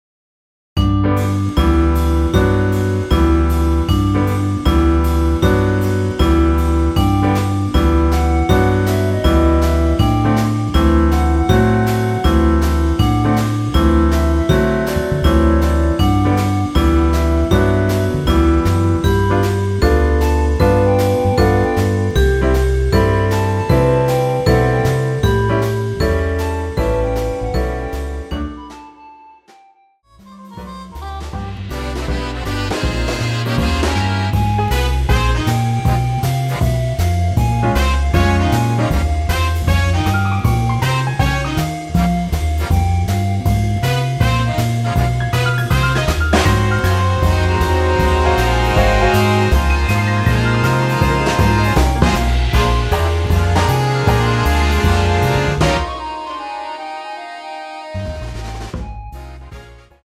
원키 멜로디 포함된 MR 입니다.(미리듣기 참조)
음정과 박자 맞추기가 쉬워서 노래방 처럼 노래 부분에 가이드 멜로디가 포함된걸
앞부분30초, 뒷부분30초씩 편집해서 올려 드리고 있습니다.